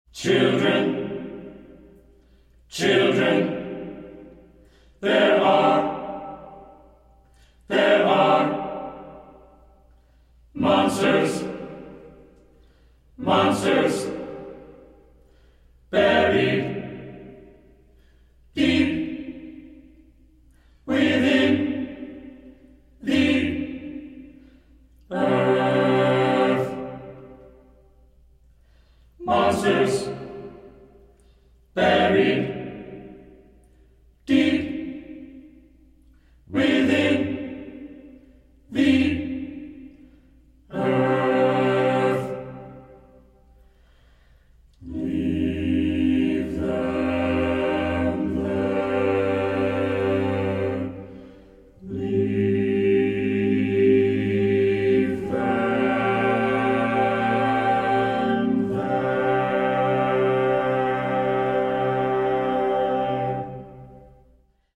• Genres: Choral Music